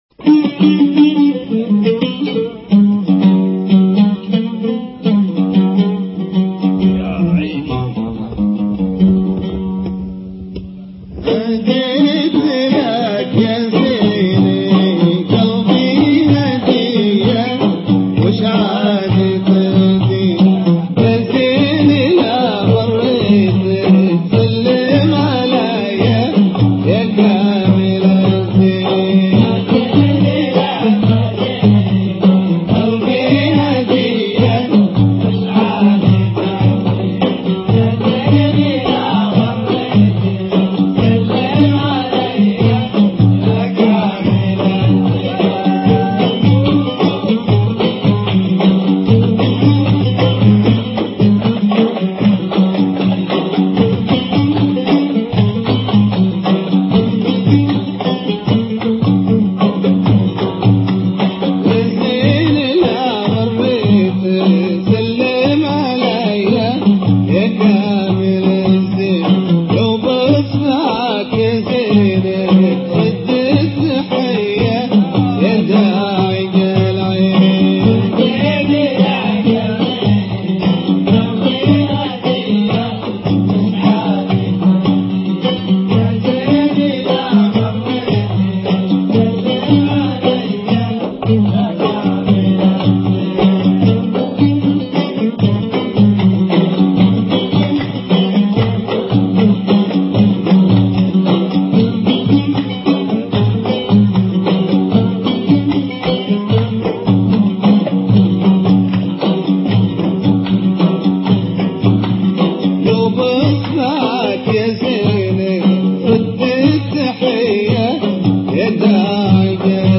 صوت لا يمرّ برئيتين ولا حنجرة , صوت غير مفلتر .